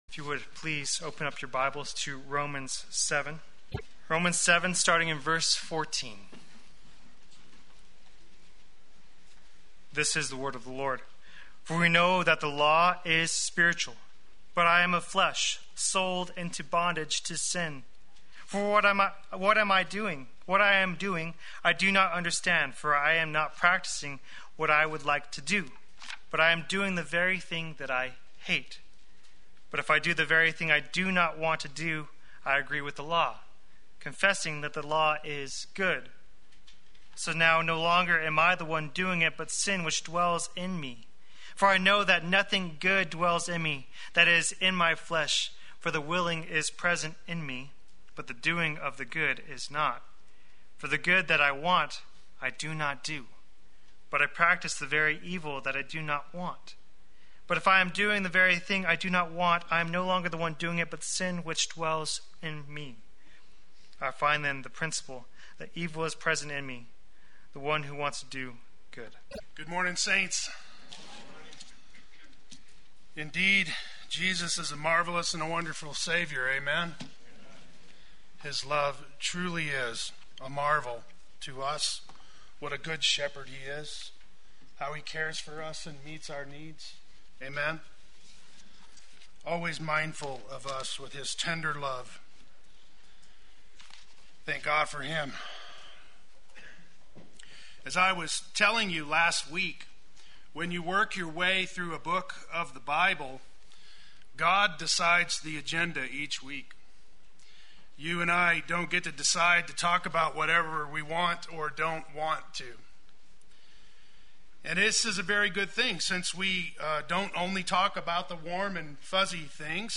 Play Sermon Get HCF Teaching Automatically.
The Law and the Sinful Nature Sunday Worship